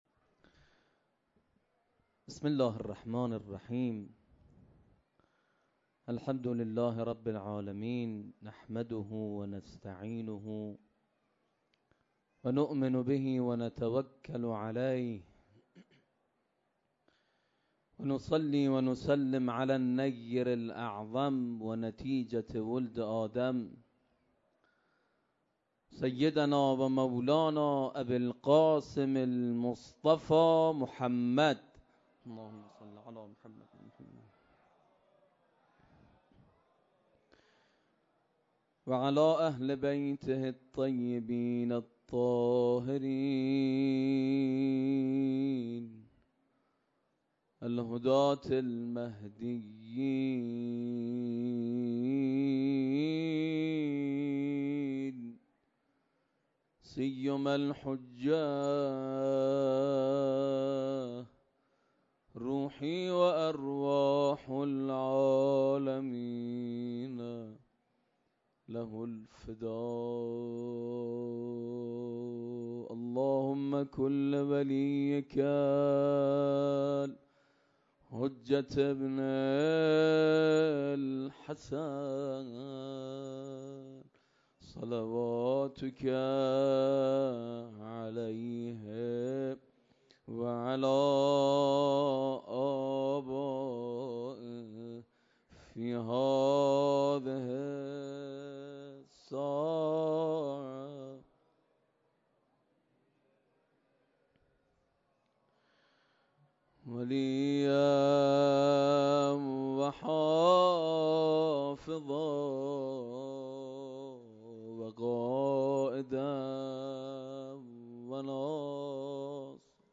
مراسم مسجد مقدس جمکران